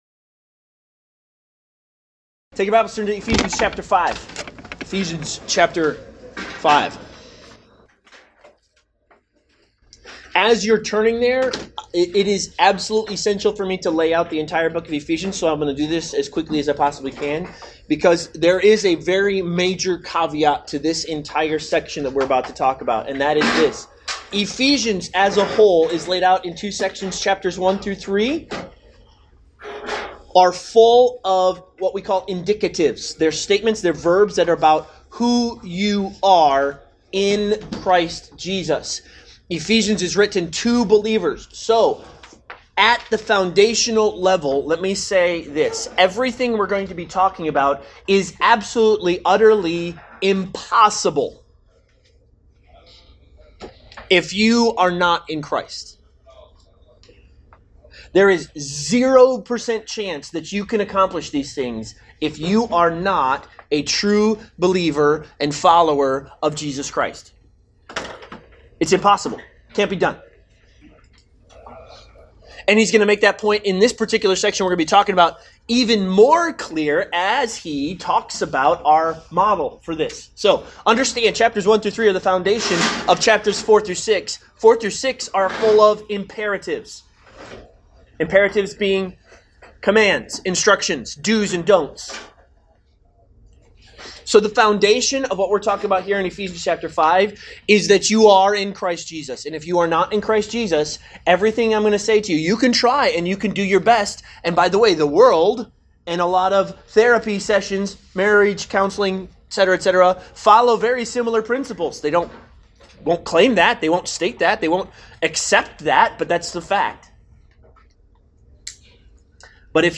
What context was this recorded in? Service Type: Men's Breakfast